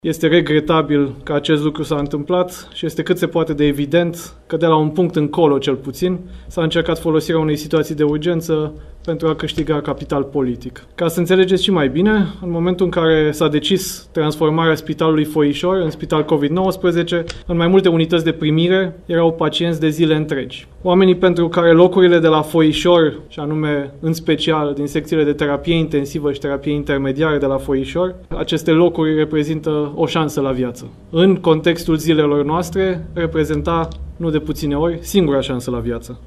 Într-o declarație de presă susținută în această după amiază, Vlad Voiculescu spune că discuția de intrare a Spitalului Foișor în circuitul Covid-19 datează de peste un an de zile